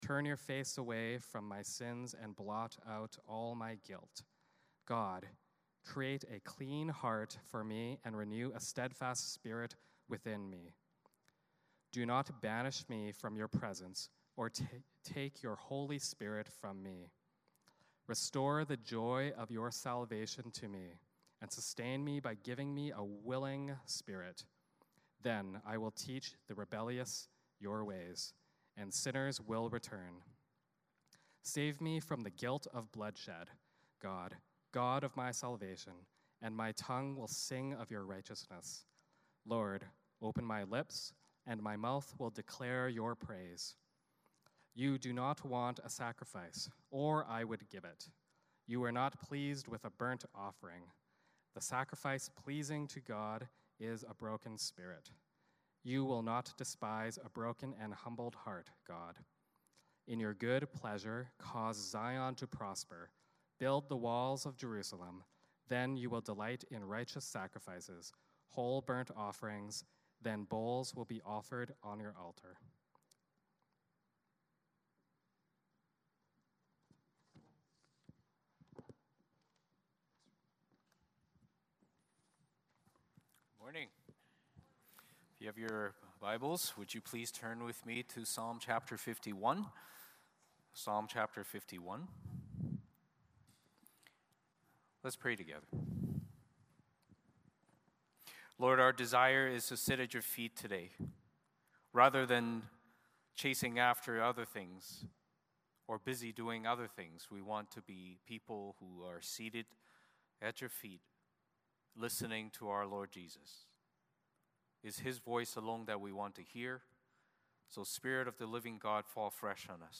Service Type: Sunday Morning Service Passage